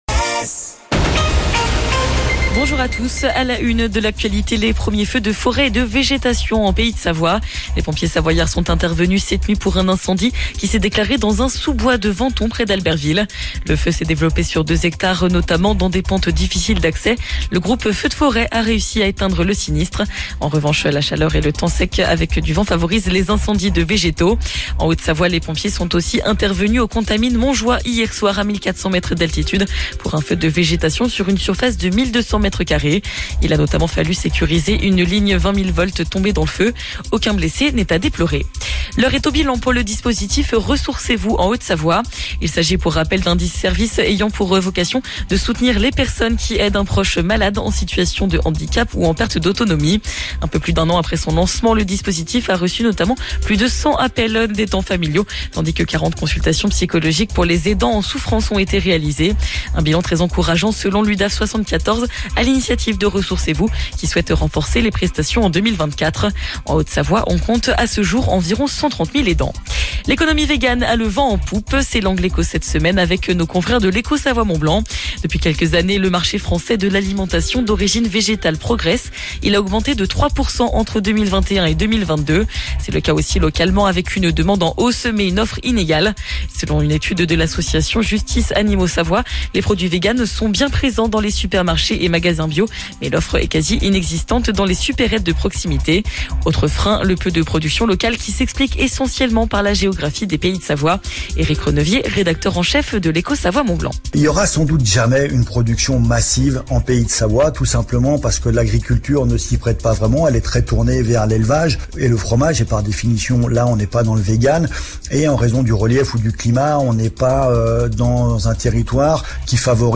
Brève radio ODS